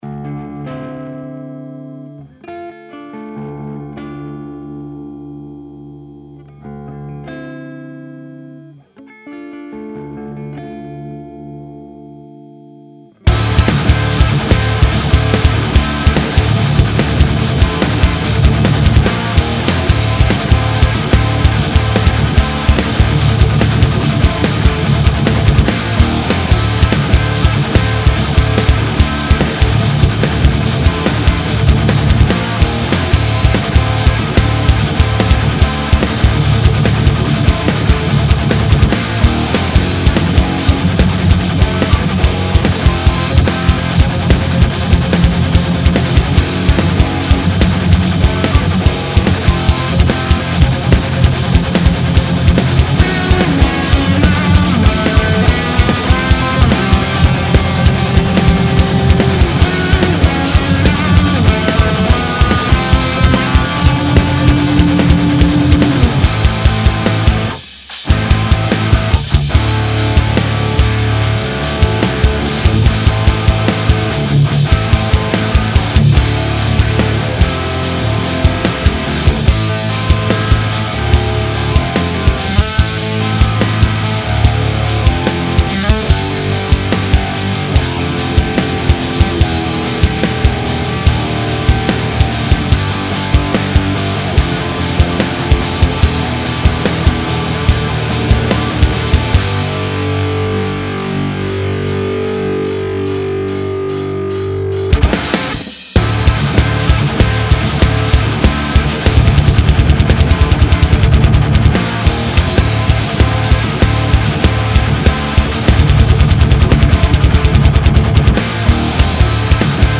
Un petit test de production, sans prétention (juste 4 riffs et une batterie en mode copié collé - zéro humanisation, etc….), je cherche à obtenir le son de rythmique le plus gros possible, avec le moins de gain possible (et évidemment, je veux que ça sonne tight aussi, tant qu'a faire).
Pas de post EQ pour rien du tout. Gros limiteur bourrin FG-X au master (mais je pense que c'est obvious, la).
A part les basses des grattes qui résonnent trop je trouve ça très réussi. Bon grain, ça pète.